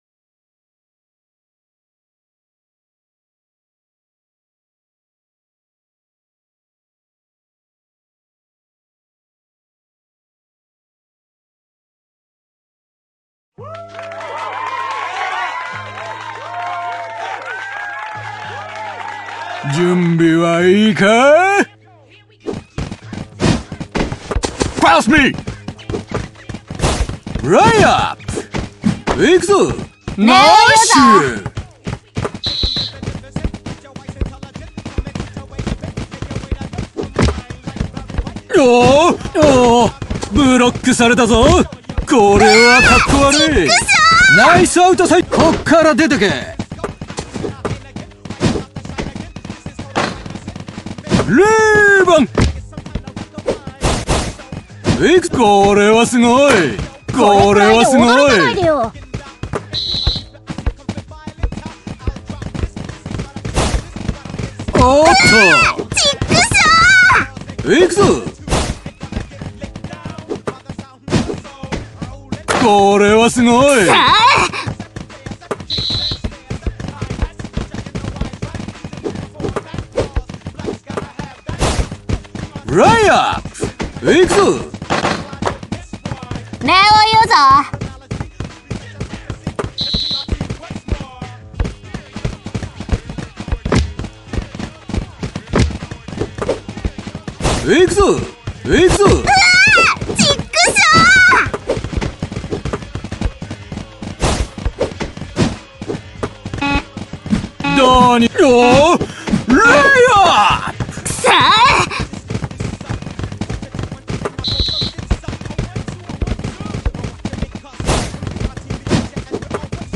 3on3対戦ゲーム、アプリ《フィーバーダンク》のプレイ動画です。 実況は無しの試合している光景だけになりますのでご了承を。